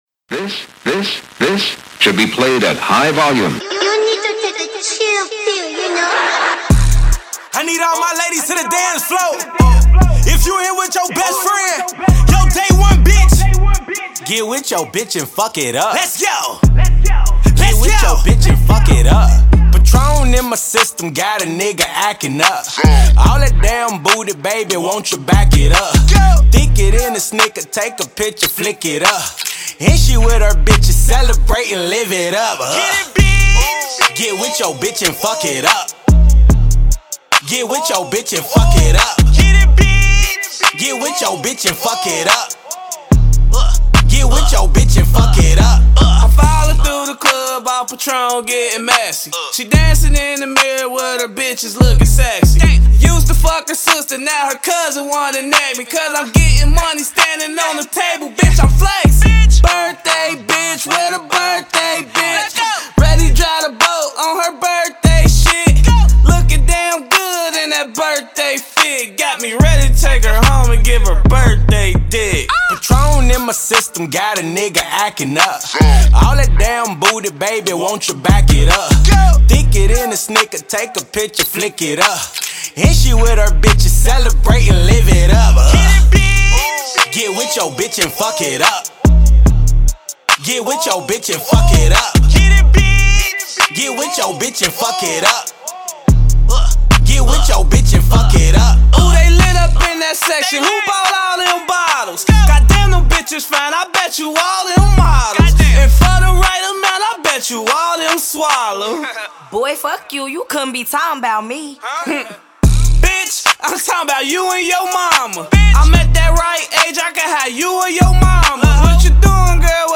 Hiphop
This clever club delivery is rather a catchy party banger